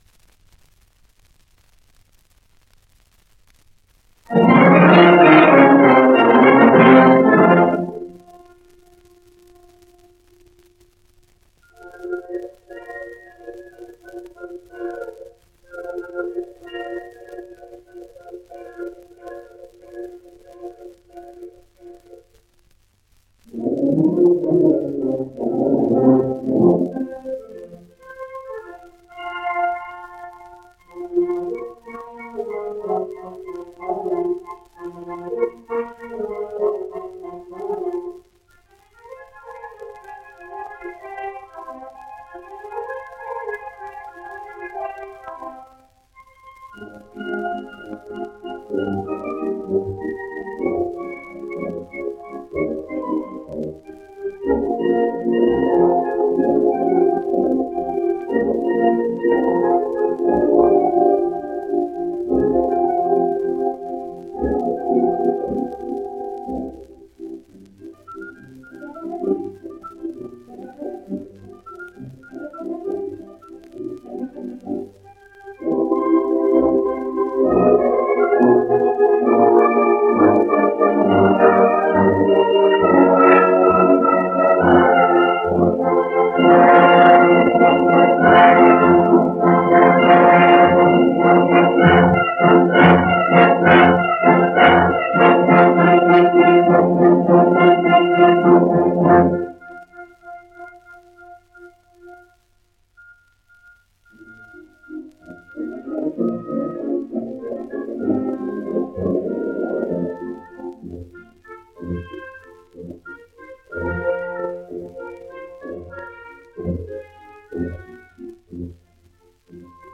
El baile de Luis Alonso: Intermedio (sonido mejorado)